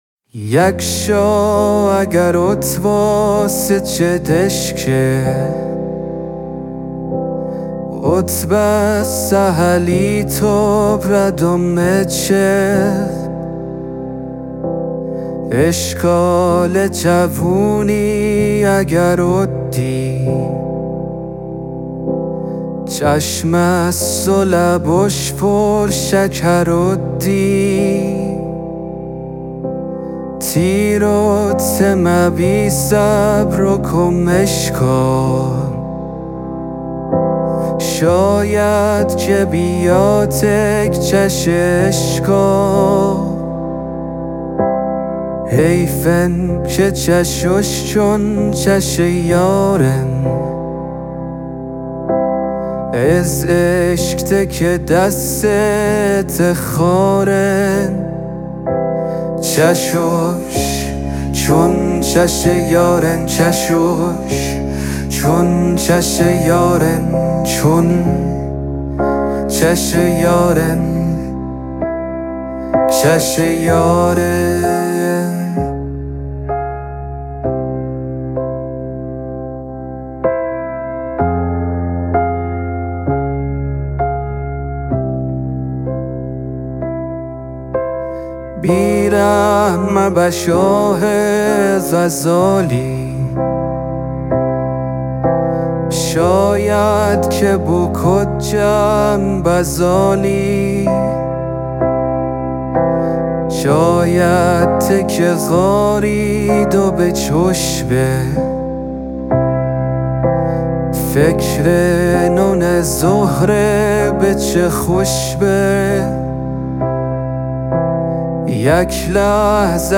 در فضایی عاشقانه
ترانه گراشی